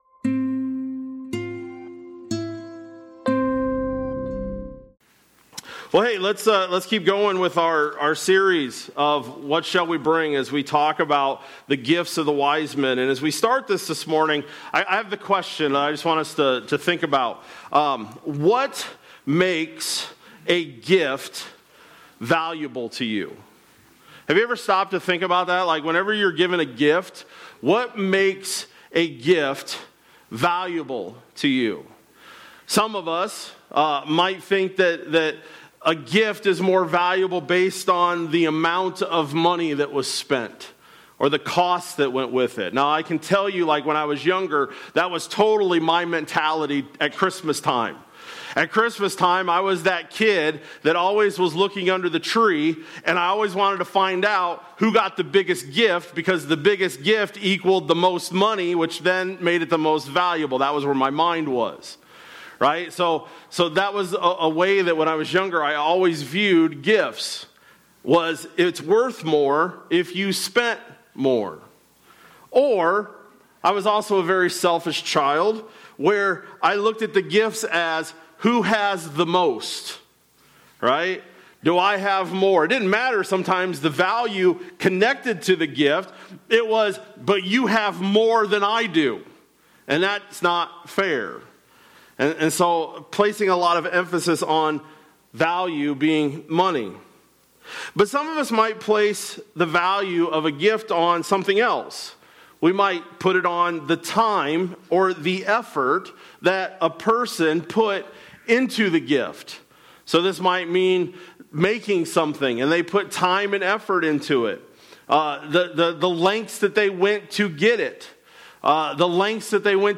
Dec-21-25-Sermon-Audio.mp3